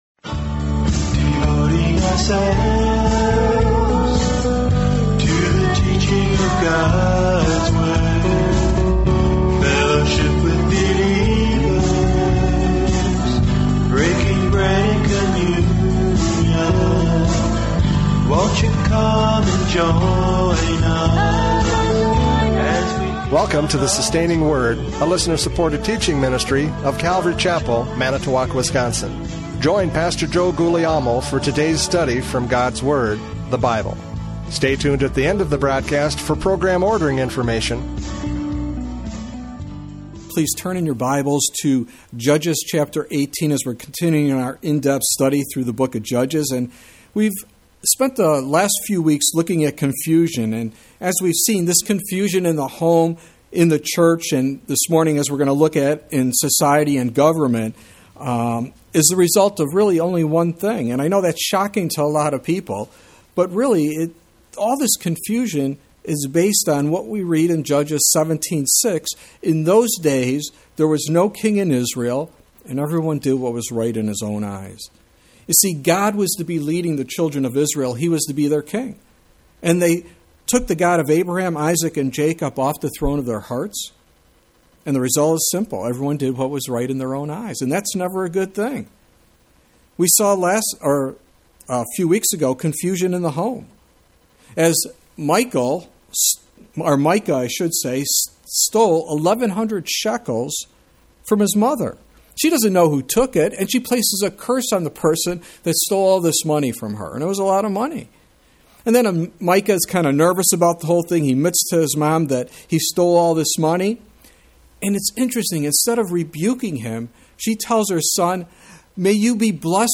Judges 18 Service Type: Radio Programs « Judges 17:6-13 Confusion in the Church!